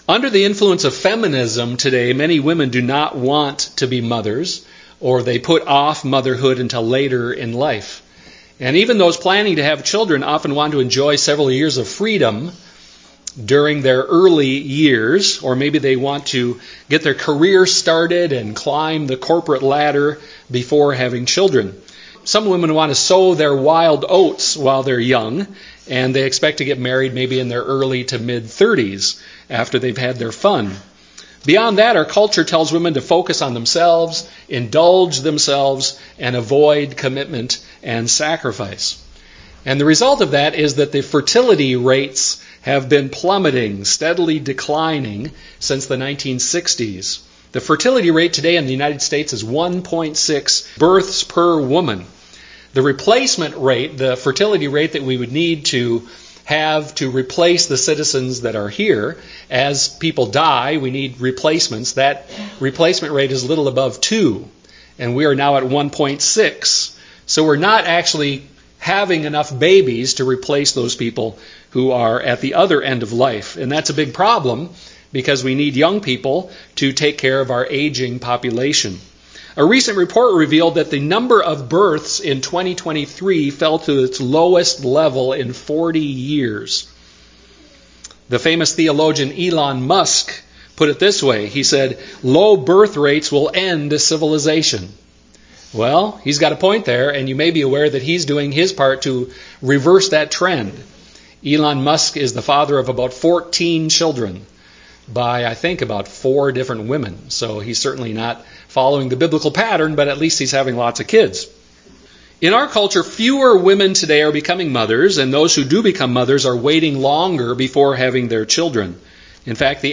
Proverbs 31:10-31 Service Type: Sunday morning worship service Happy Mother’s Day.